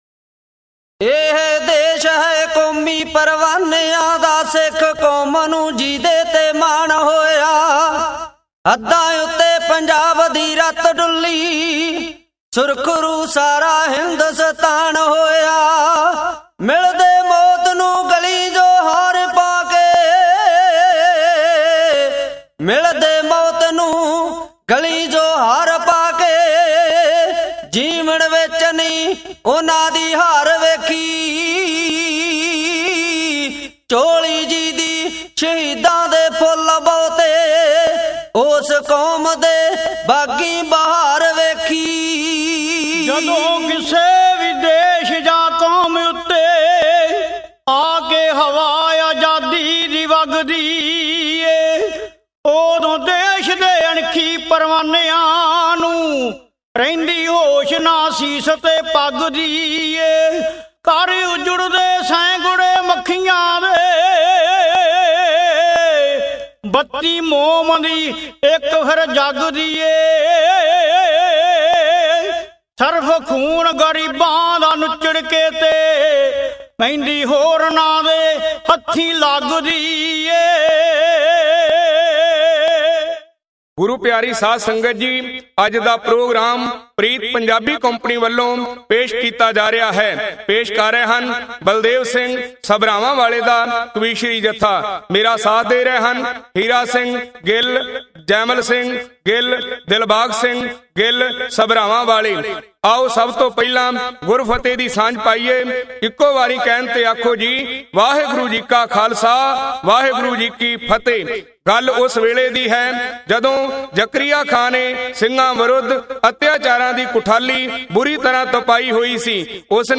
Genre: Dhadi Vaara